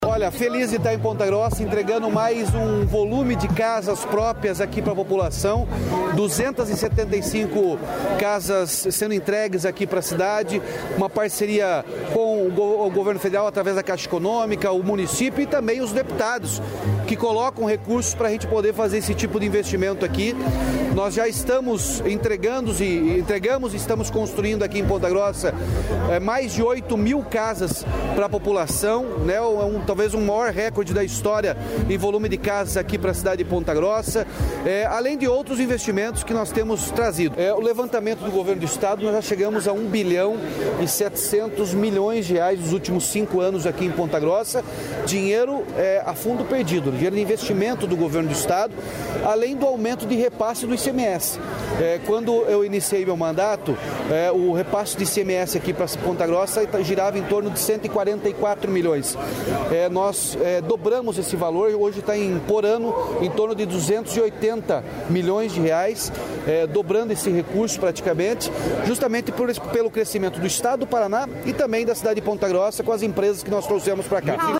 Sonora do governador Ratinho Junior sobre a entrega de 275 casas em Ponta Grossa